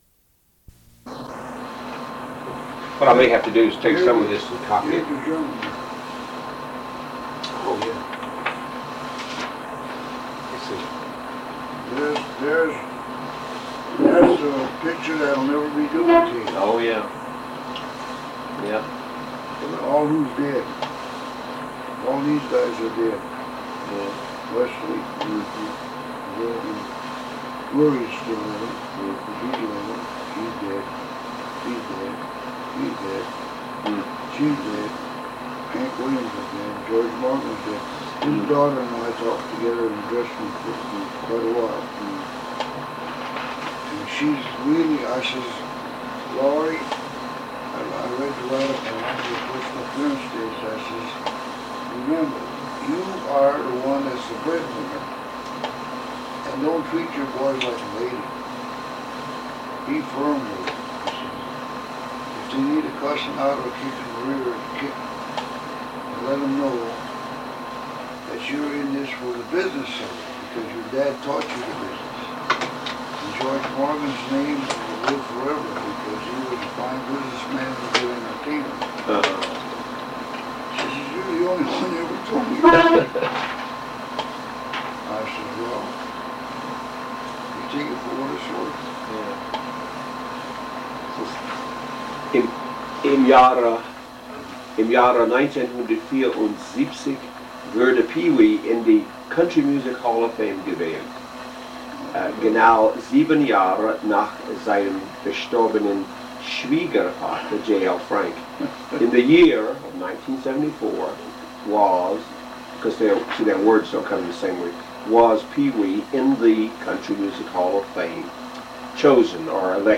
Oral History Interview with Pee Wee King